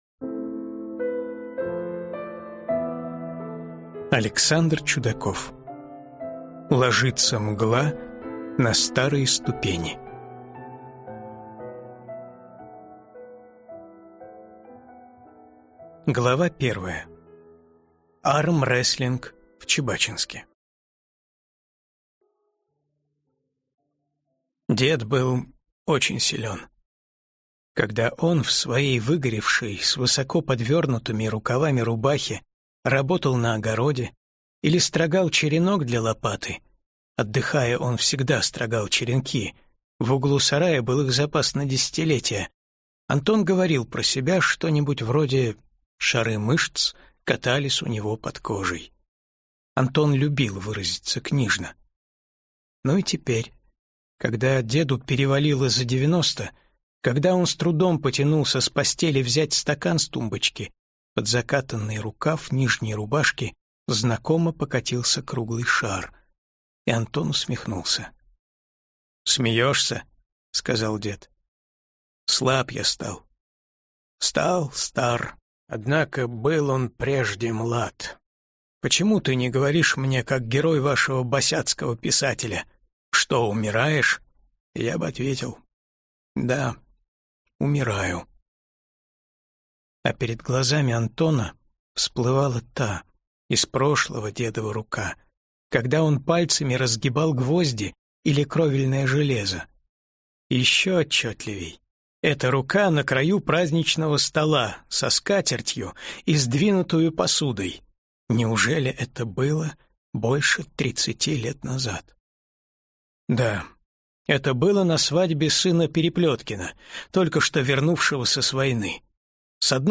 Аудиокнига Ложится мгла на старые ступени - купить, скачать и слушать онлайн | КнигоПоиск